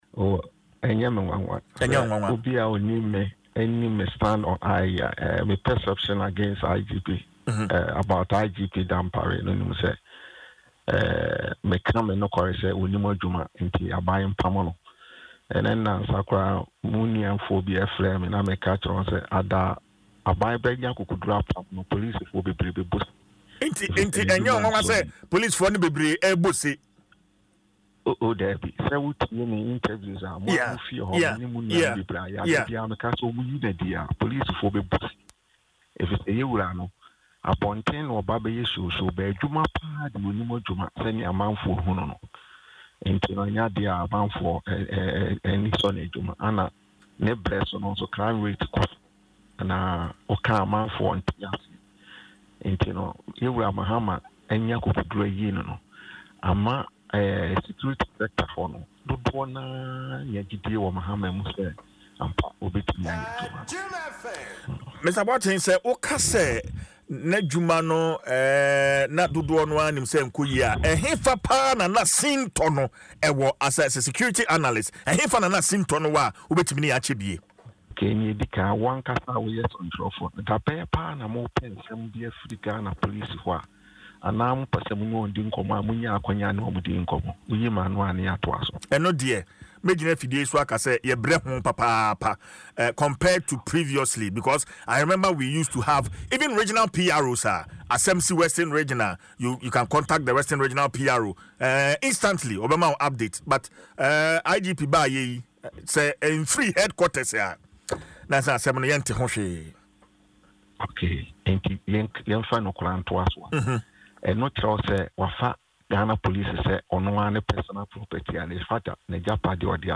Speaking in an interview on Adom FM’s morning show Dwaso Nsem